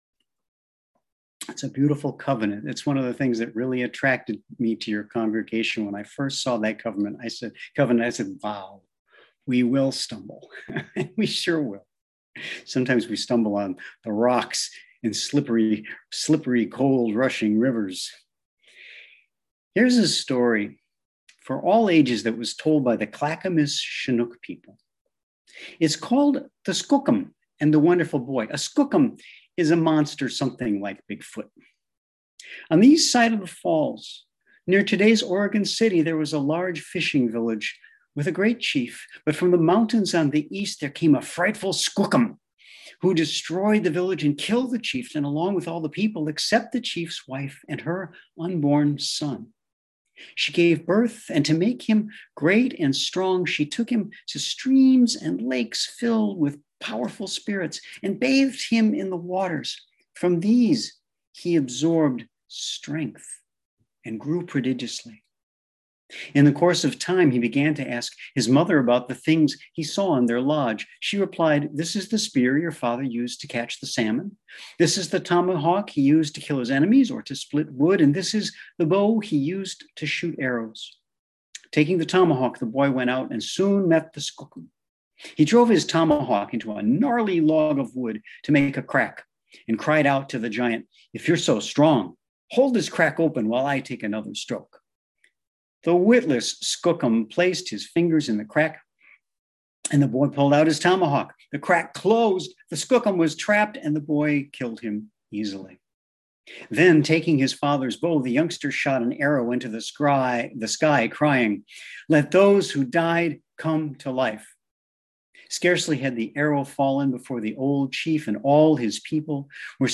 Sermon Archive | Wy'east Unitarian Universalist Congregation